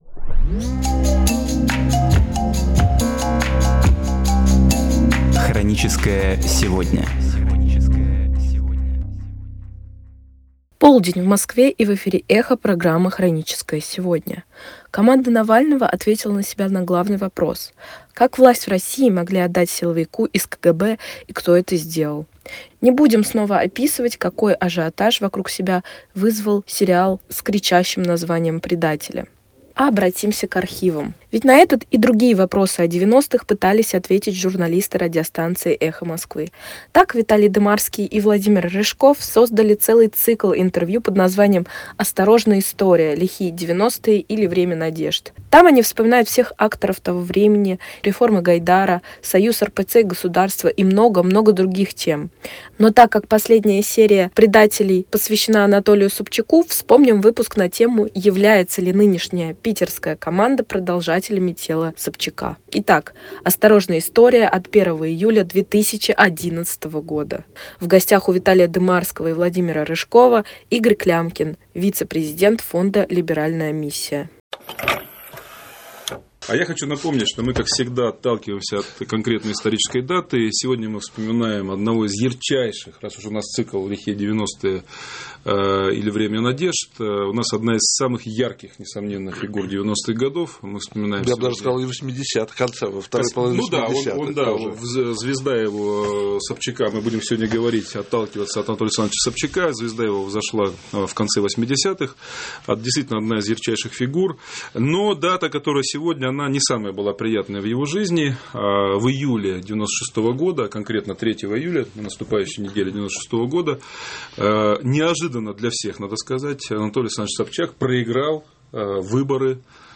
Архивные передачи «Эха Москвы» на самые важные темы дня сегодняшнего.